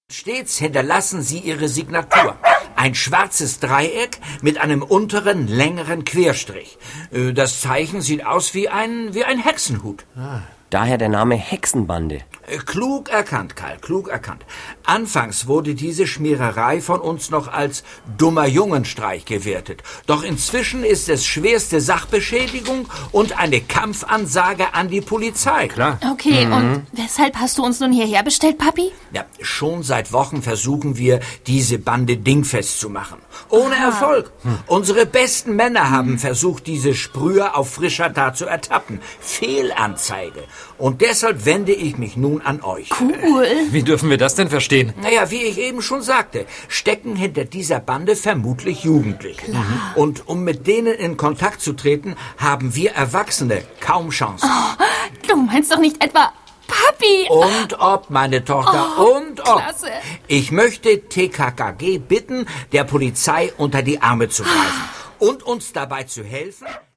Ravensburger TKKG 164 - Operation Hexen-Graffiti ✔ tiptoi® Hörbuch ab 6 Jahren ✔ Jetzt online herunterladen!